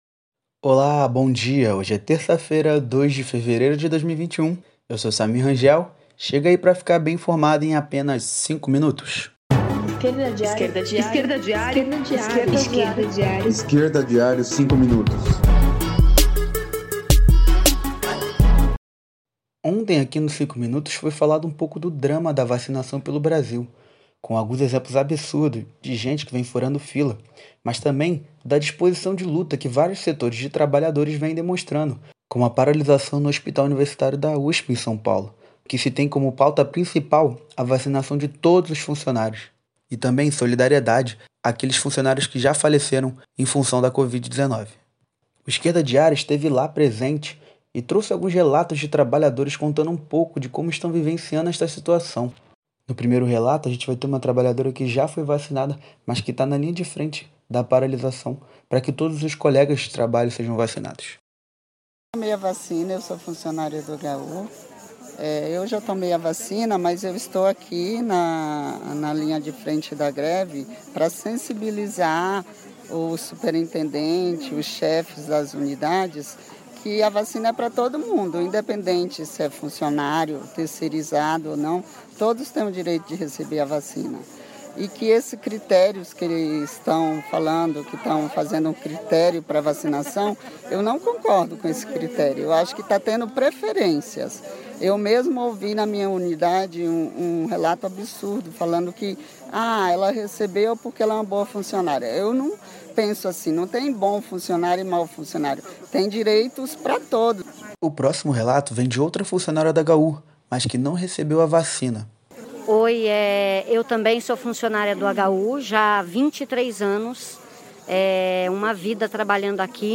Cobertura especial sobre a paralisação do Hospital Universitário da USP com relato dos trabalhadores.